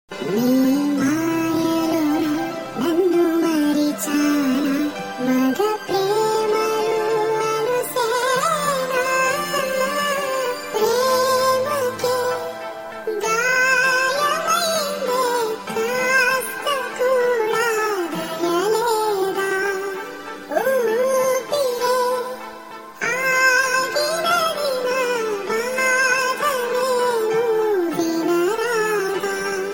best flute ringtone download | love song ringtone
romantic ringtone download